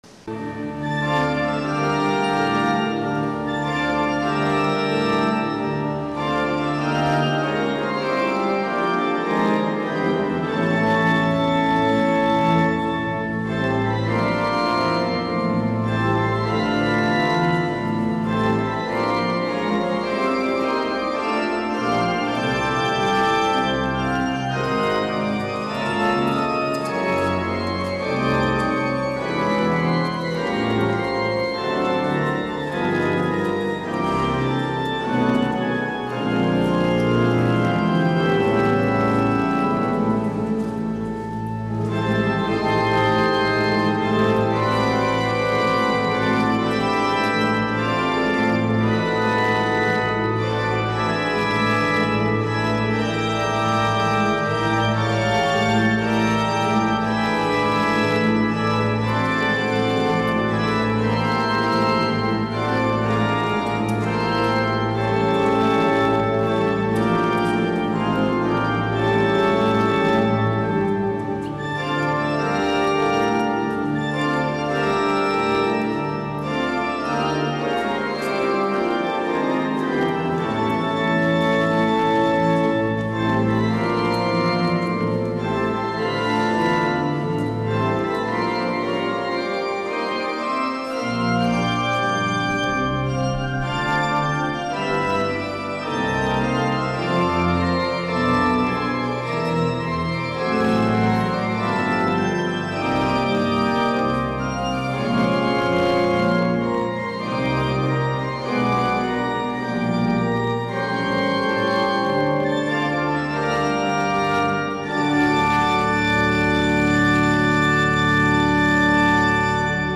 Der Lesungstext
Blick in den Altarraum der Peterskirche